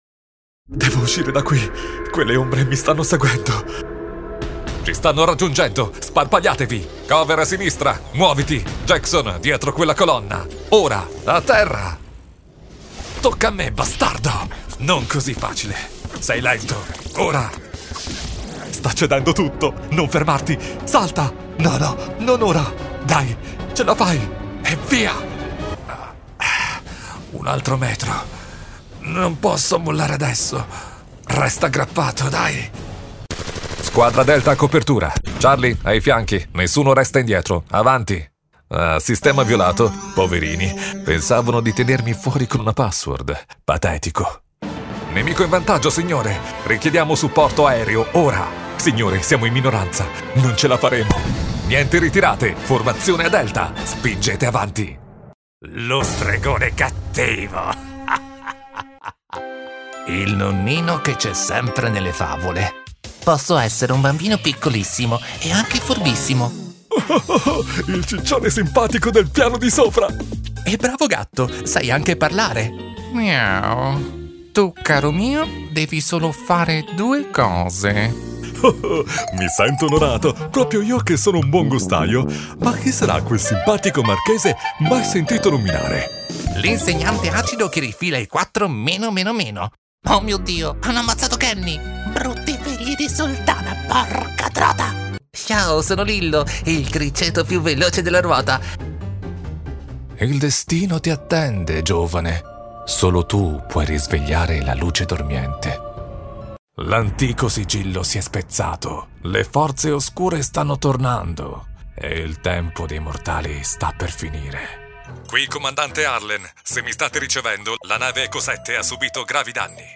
Male
Approachable, Authoritative, Character, Confident, Conversational, Cool, Corporate, Deep, Energetic, Friendly, Funny, Natural, Smooth, Soft, Upbeat, Versatile, Warm, Young
Warm for narrations, dynamic for commercials, smooth and professional for presentations
Microphone: Neumann Tlm 103